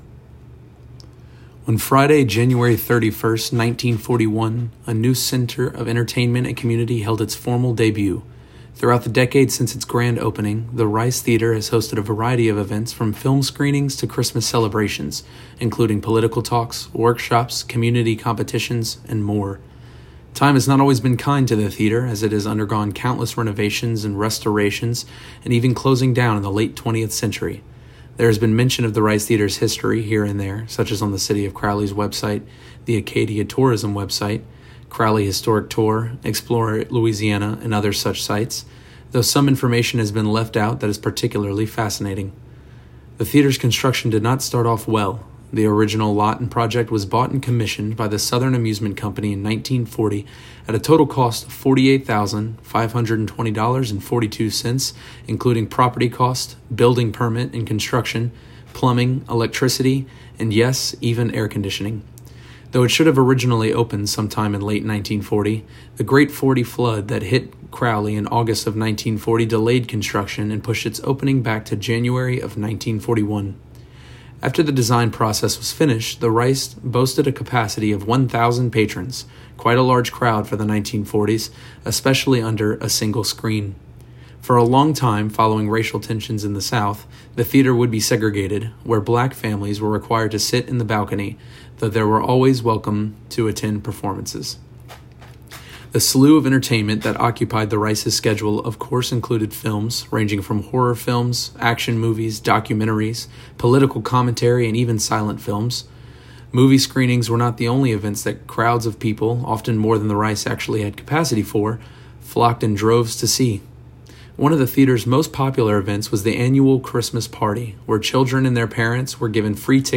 Narration of Setting Sights on the Ricem4a / 2.48 MB